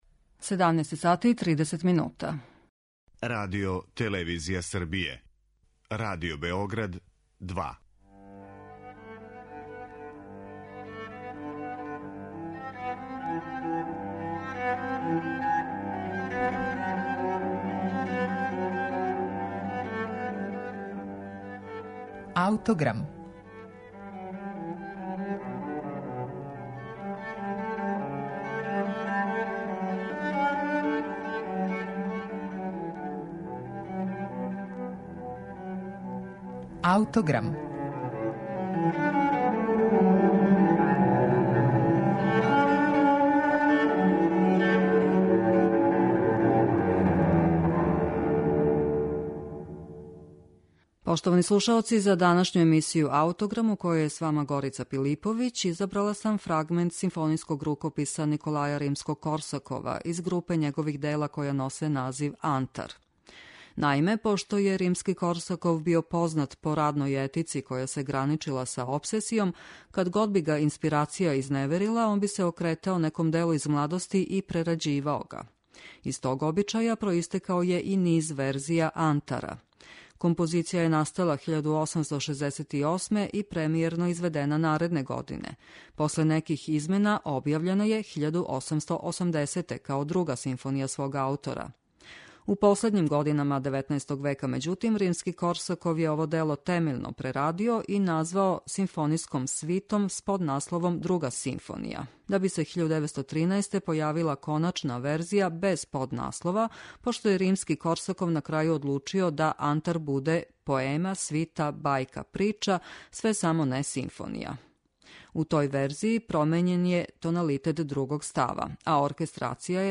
Поред Шехерезаде, Николаја Римског-Корсакова су инспирисали и други ликови из легендарне прошлости арапског света, те је 1868. компоновао симфонијску свиту под називом Антар.
маштовито оркестрираној композицији